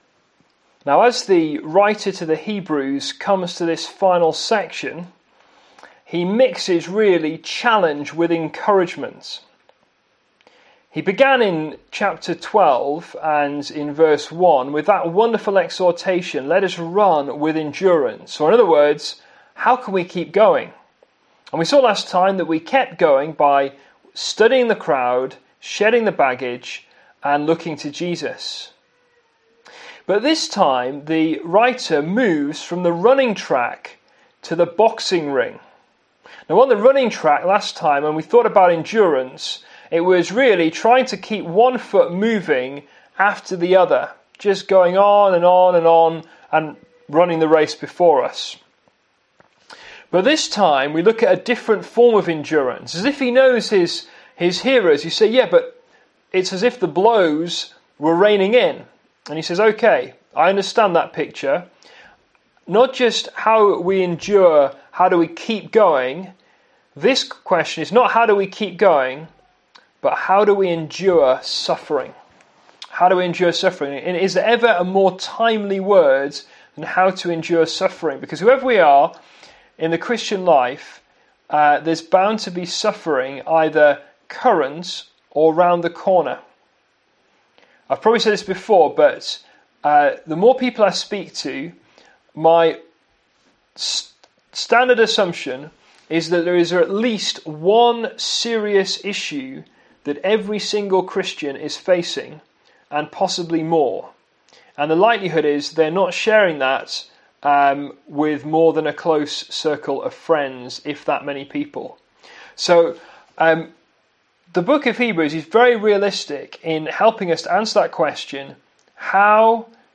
Service Type: PM